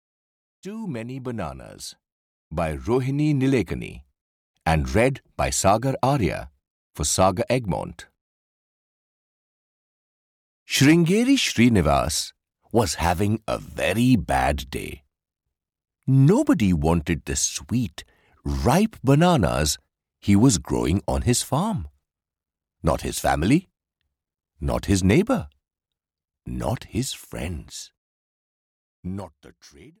Too Many Bananas (EN) audiokniha
Ukázka z knihy